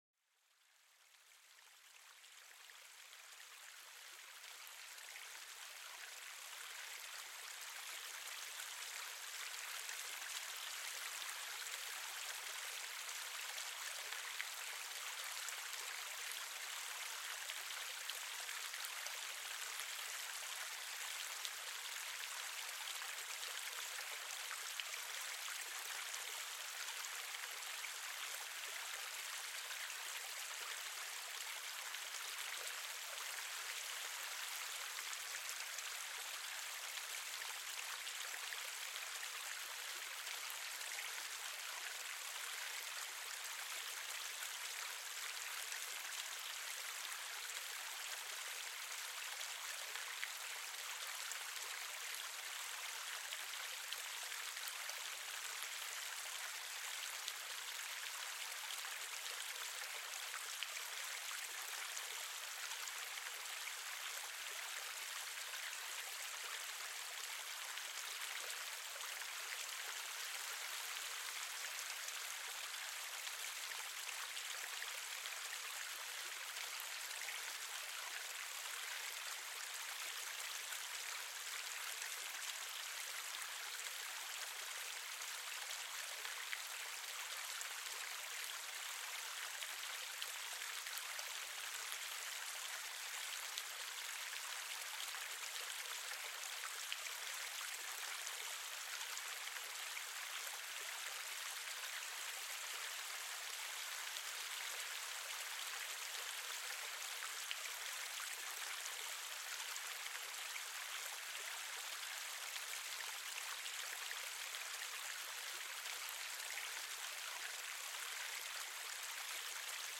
El Sonido Relajante del Río: Una Melodía Natural para la Relajación